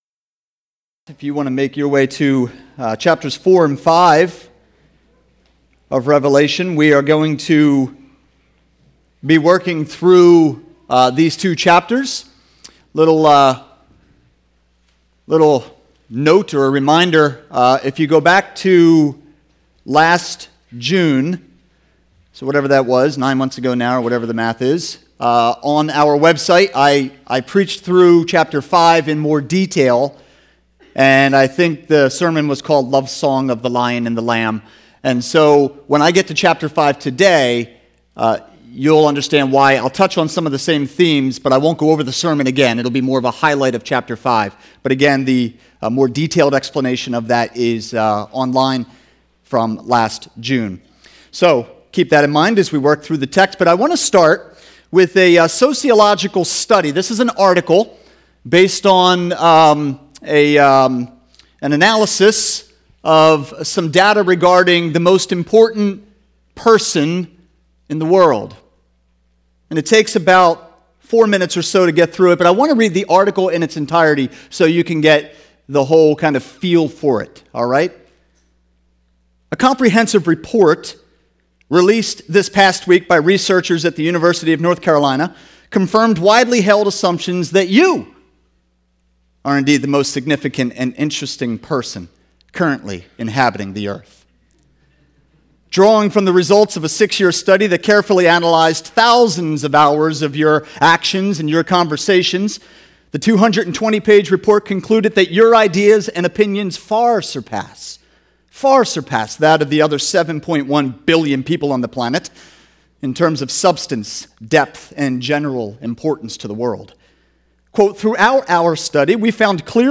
Sermons - Immanuel Church